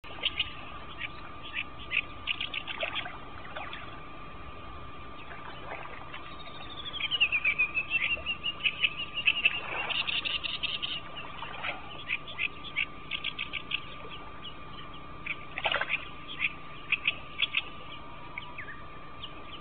寒い冬が終わり、伊豆沼に春が訪れると、岸辺のあちこちで「バシャバシャ」という音が聞こえてきます。この音は、コイやフナが産卵する時の水しぶきの音です。
初夏の伊豆沼では、岸辺のあちこちから、「ギョギョシ、ギョギョシ」という大きな鳴き声が聞こえてきます。
鳴いているのはオスで、自分の縄張りを主張するために鳴いています。よく聞くと、隣りの縄張りのオスと交互に鳴きあっていることもあります。
オオヨシキリの鳴き声（＋フナの産卵）（mp3 307KB）